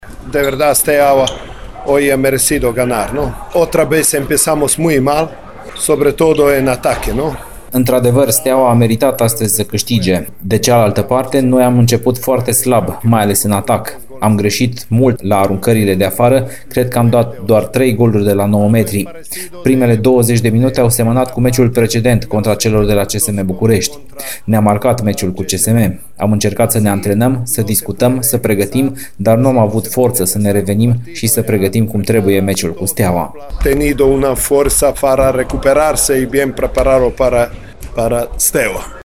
La declarațiile de după meci